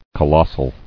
[co·los·sal]